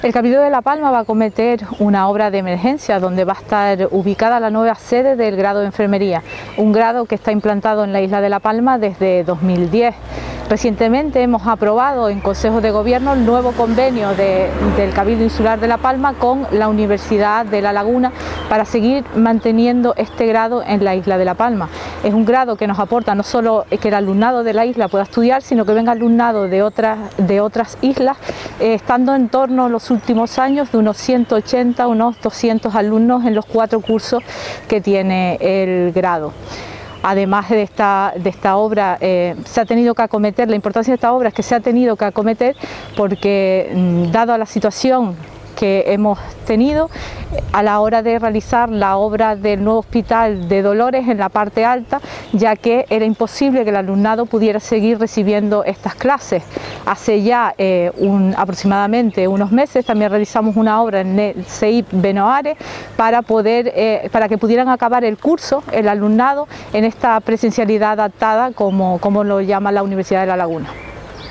Declaraciones de Susana Machín, consejera de Educación sobre la nueva sede del …